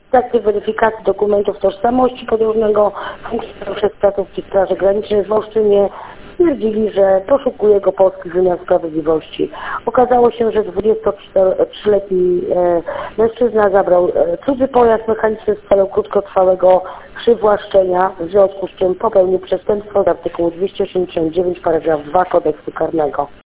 Mówiła